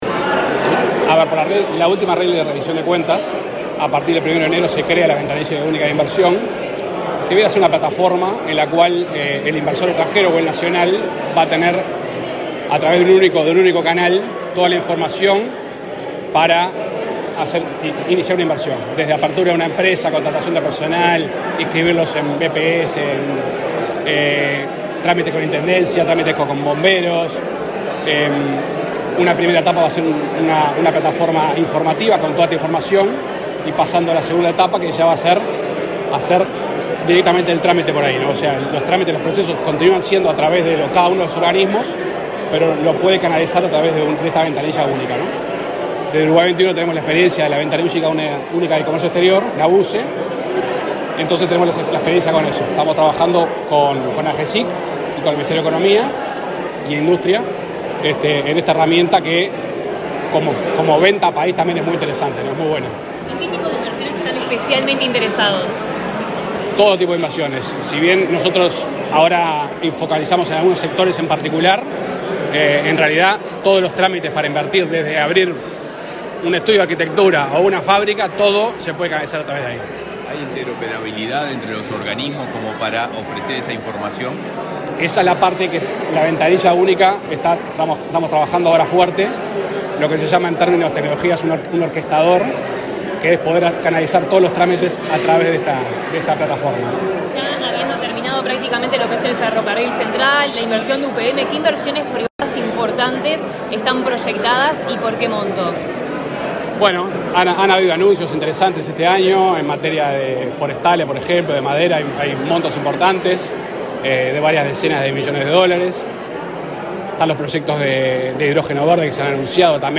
Declaraciones del director ejecutivo de Uruguay XXI, Sebastián Risso
Declaraciones del director ejecutivo de Uruguay XXI, Sebastián Risso 28/11/2023 Compartir Facebook X Copiar enlace WhatsApp LinkedIn El director ejecutivo de Uruguay XXI, Sebastián Risso, dialogó con la prensa en Torre Ejecutiva, luego de participar del lanzamiento de la Ventanilla Única de Inversión.